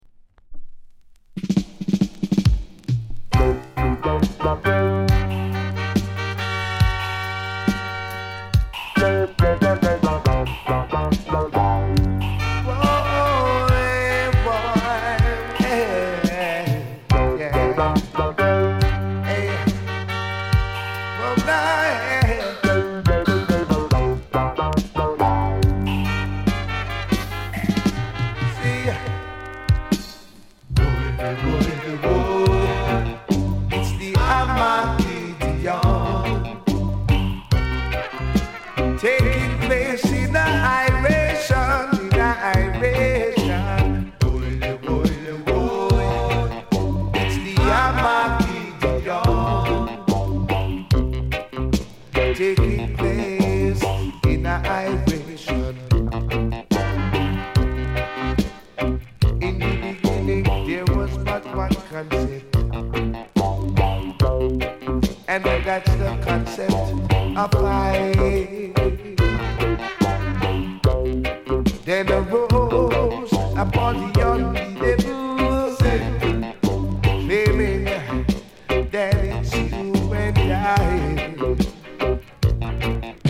UK・英 7inch/45s